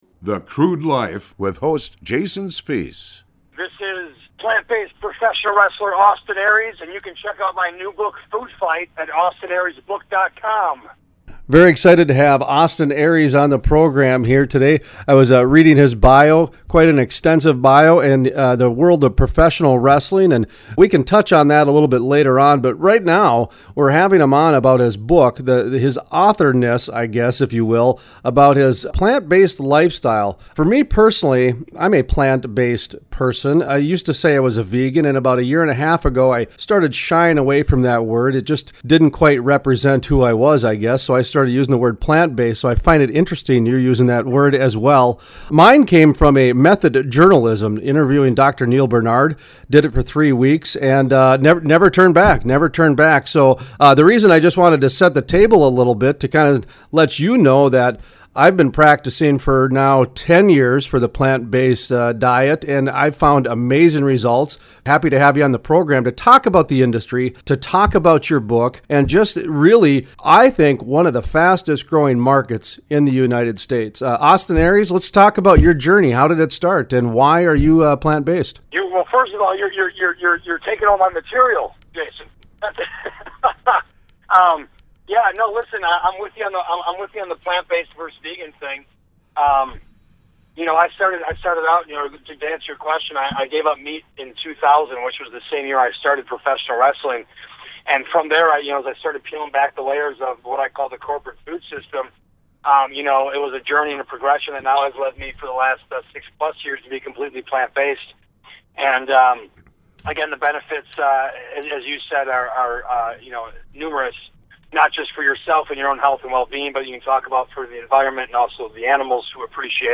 In this installment of a Decade of Discussion is from 2017, the featured guest is professional wrestler and plant-based speaker Austin Aries.